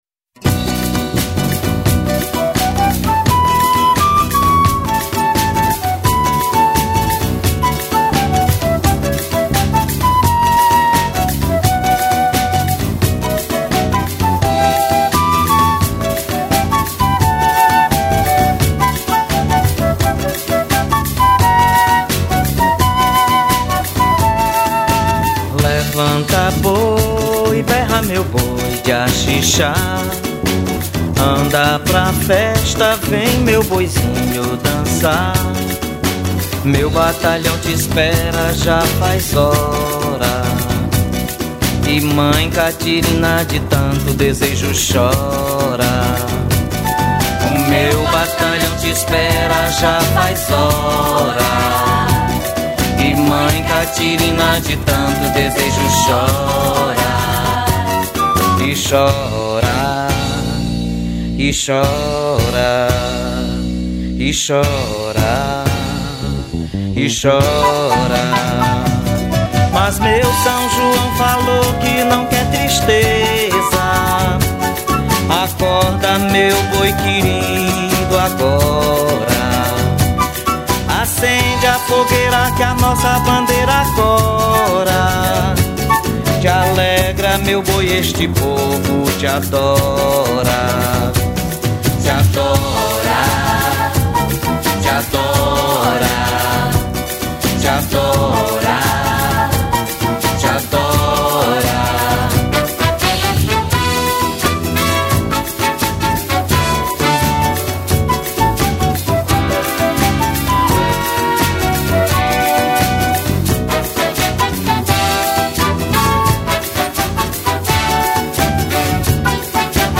03:48:00   Boi Bumbá